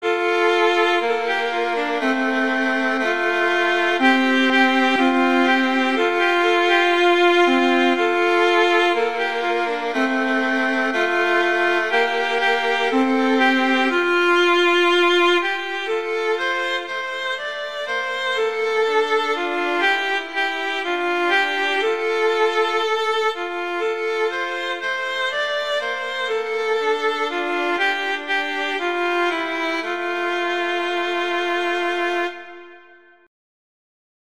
arrangements for two violins
two violins